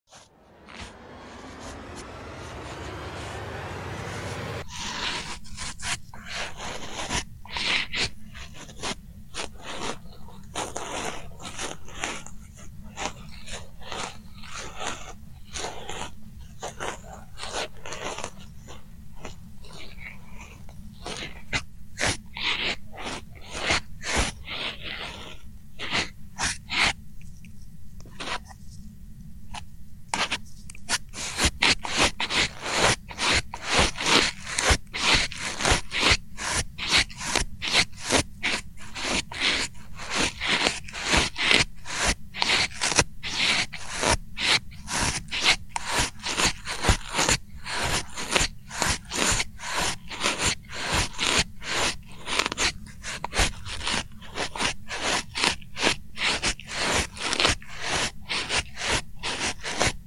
ASMR Camera Lens Cleaning Brush sound effects free download
ASMR Camera Lens Cleaning Brush | Soft Bristle Sounds for Relaxation
In this ASMR video, I use a soft brush designed for cleaning camera lenses, creating gentle bristle sounds. The light strokes and delicate textures provide a soothing and relaxing experience, perfect for helping you unwind and feel tingles.